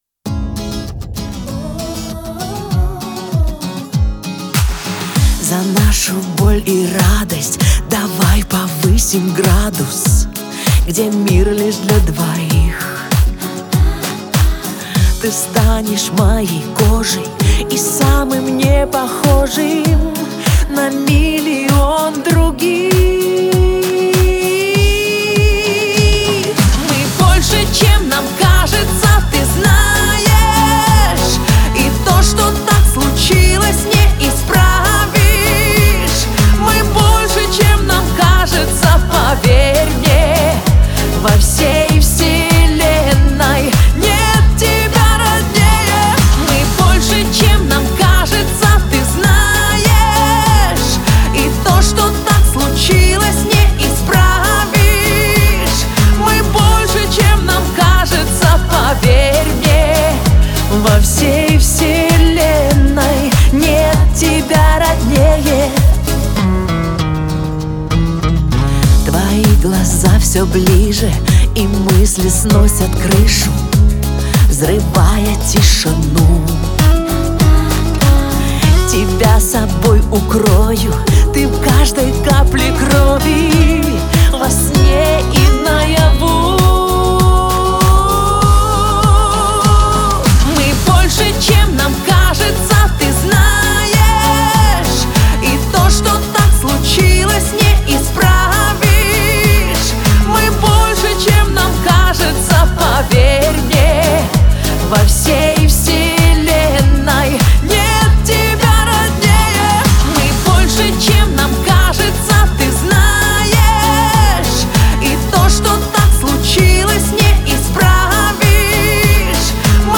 это трогательная и эмоциональная песня в жанре поп